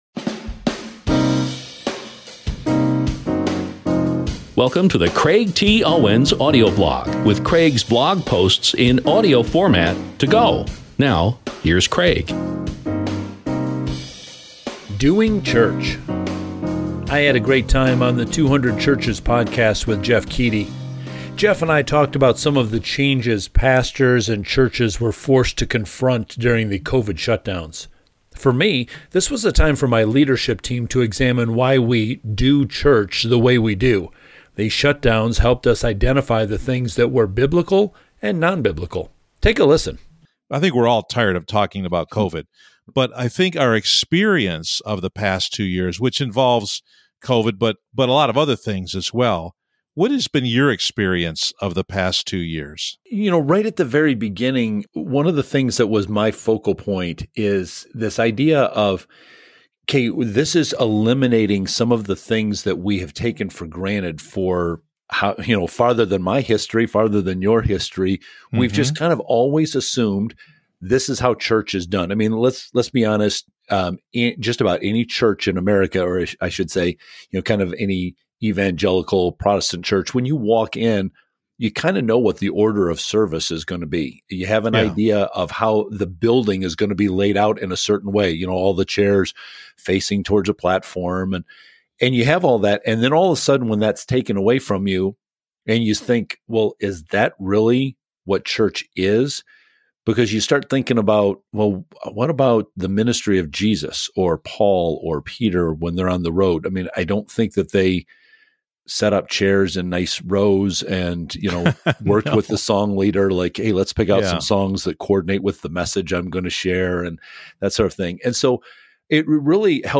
I’ll be sharing more clips from this 200churches interview soon, so please stay tuned.